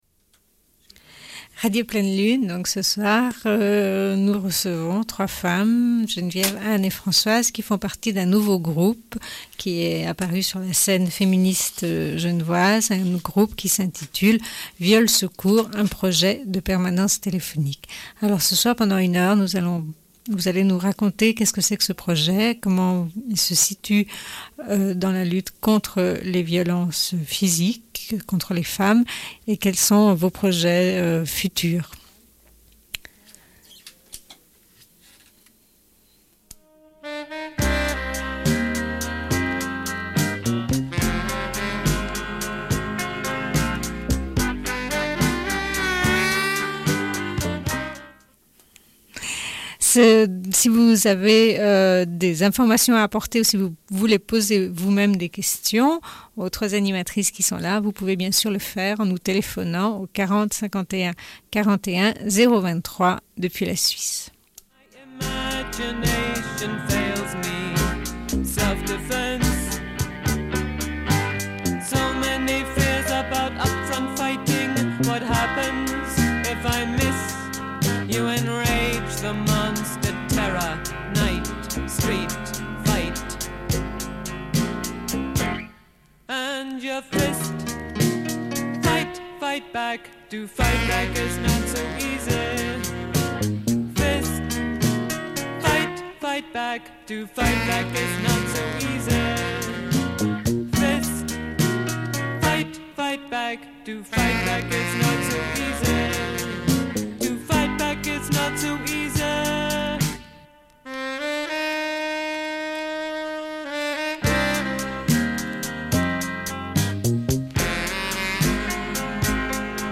Une cassette audio, face A31:31